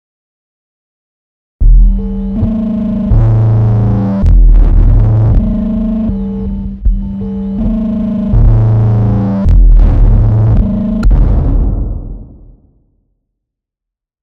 При разных значениях этого параметра можно получать довольно разное звучание на одних и тех же настройках: